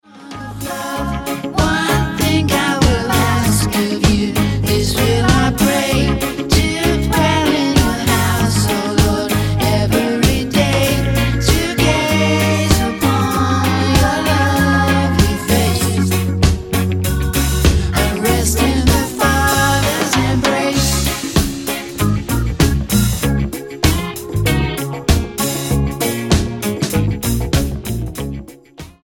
STYLE: Rock
wispy vocals are bolstered by layered background vocals